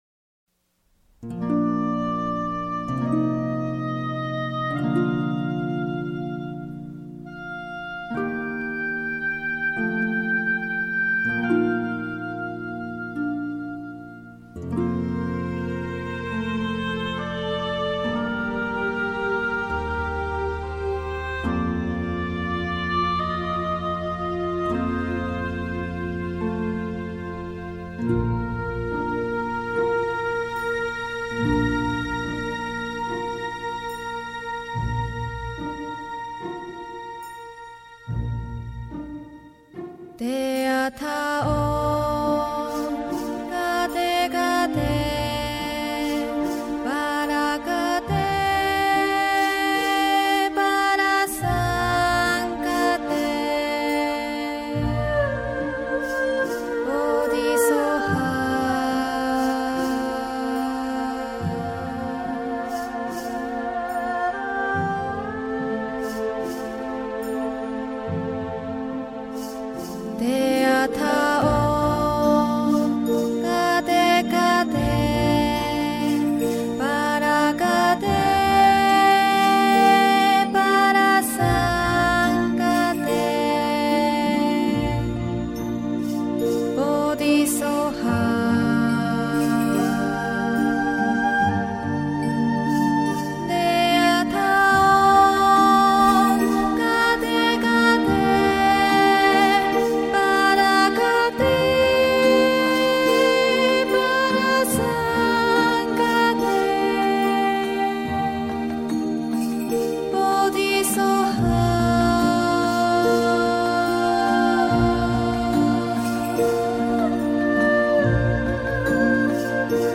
佛音 真言 佛教音乐 返回列表 上一篇： 陀罗尼(梵文